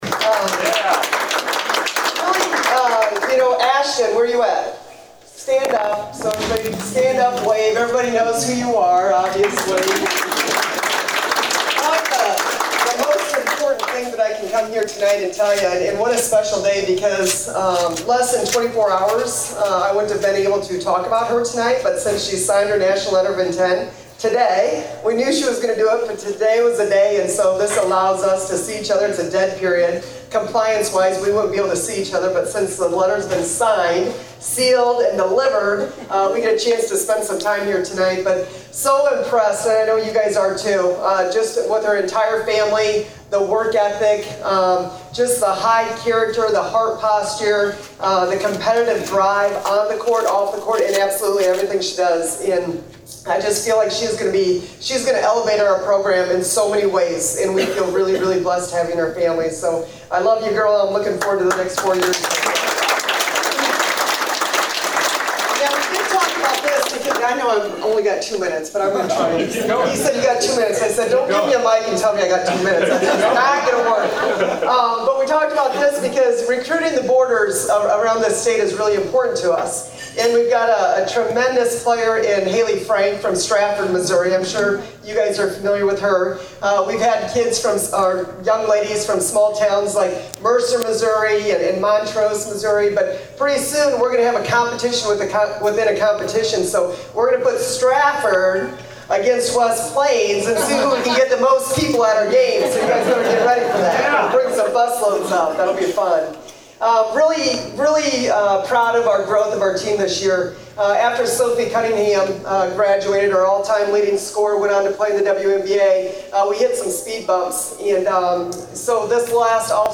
Mizzou Lady Tigers head basketball coach Robin Pingeton talks to the crowd at the West Plains Country Club.